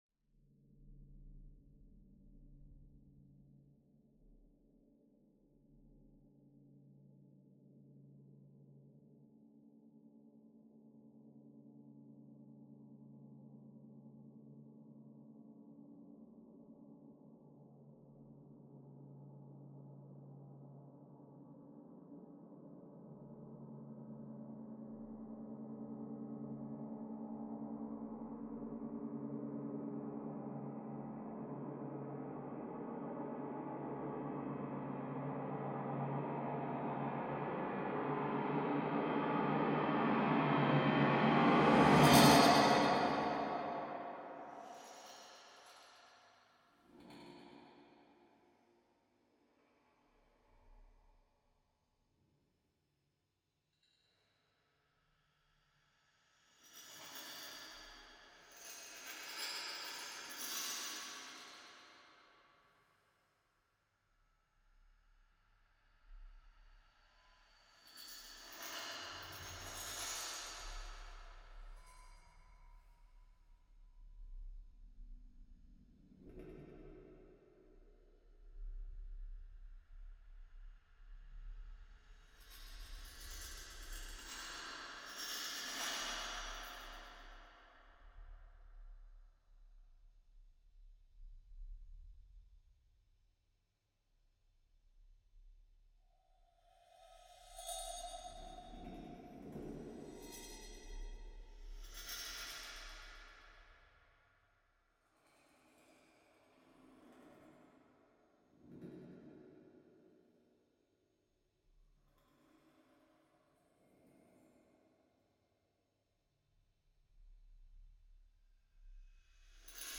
for soprano and piano
Orchestration: Für Sopran, Klavier und Elektronik